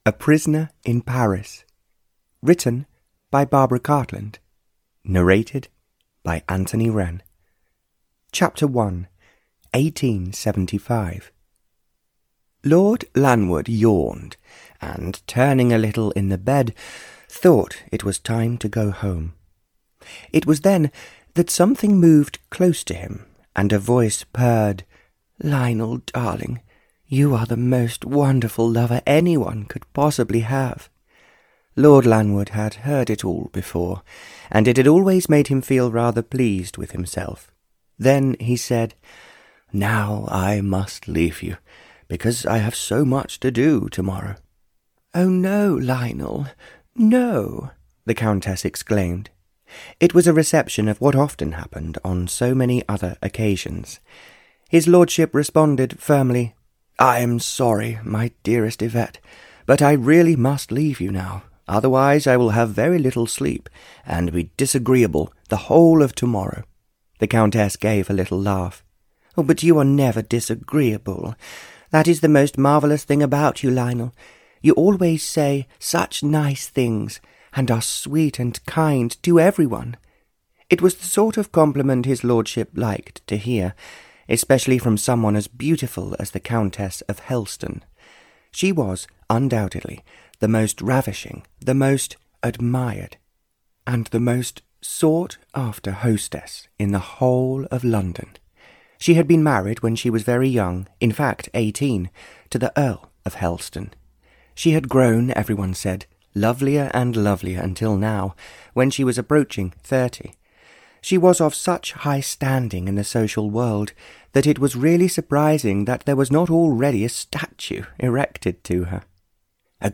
Audio knihaA Prisoner in Paris (Barbara Cartland's Pink Collection 109) (EN)
Ukázka z knihy